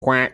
Cuack.mp3